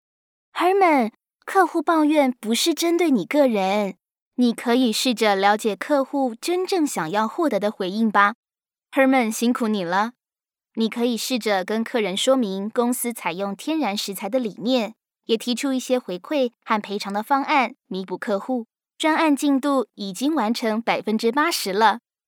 Traditional (Tayvan) Seslendirme
Kadın Ses